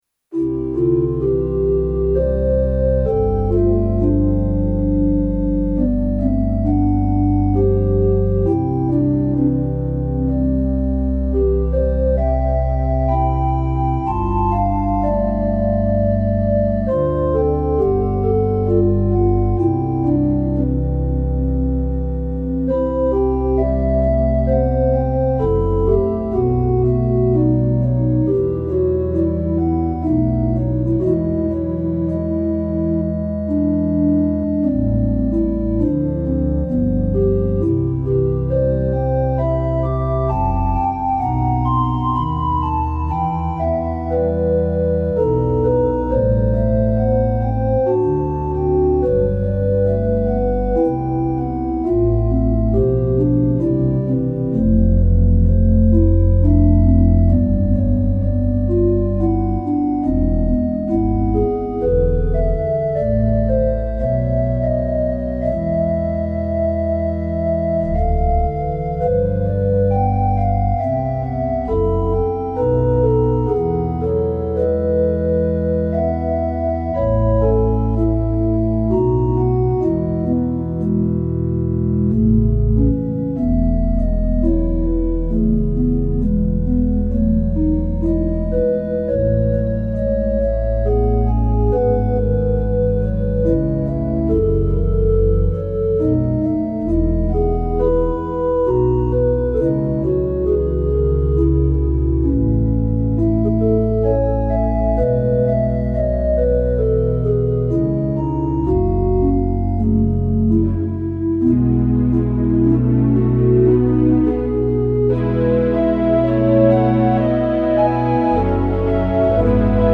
Rodgers 205 Hybrid Organ (circa 1978)